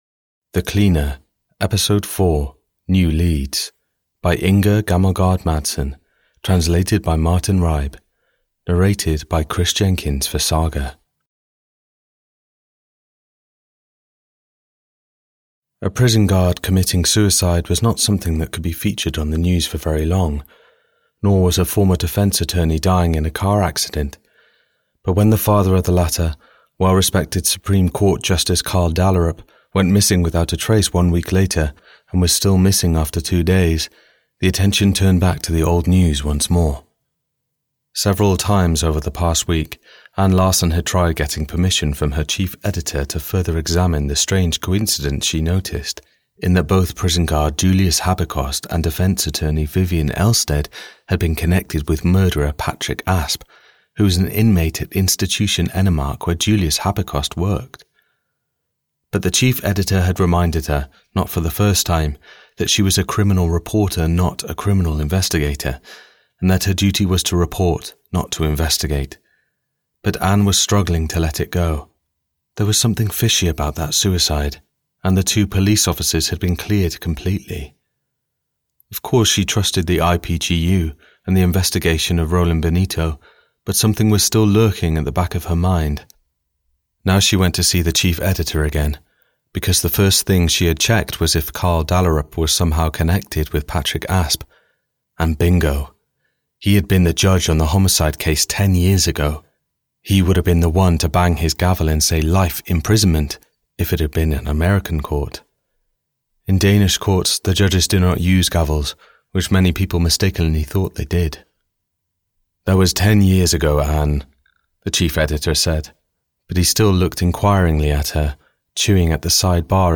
Audio knihaThe Cleaner 4: New Leads (EN)
Ukázka z knihy